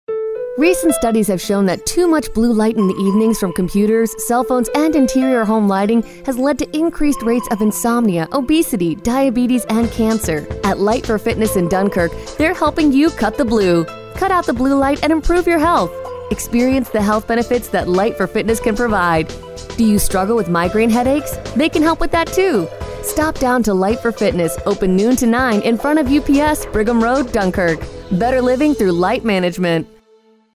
LIGHT FOR FITNESS GENERAL RADIO AD #1